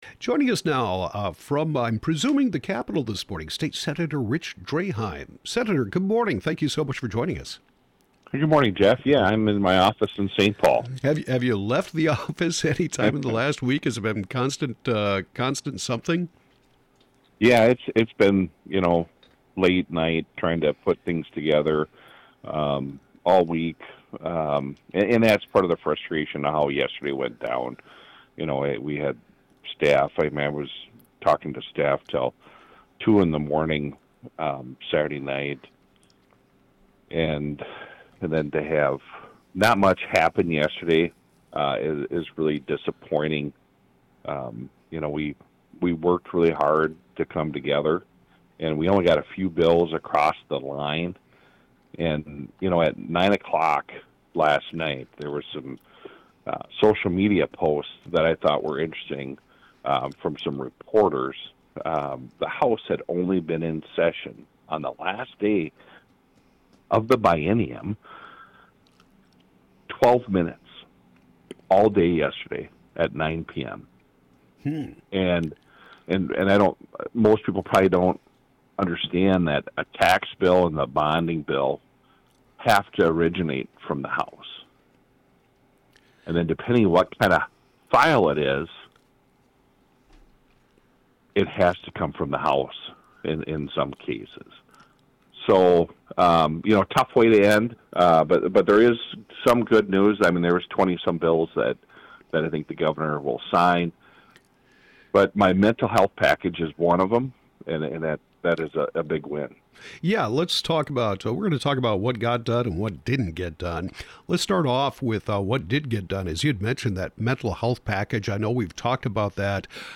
District 20 State Senator Rich Draheim discusses the close of the Legislative Session, what got done and didn't get done on the KYMN Morning show: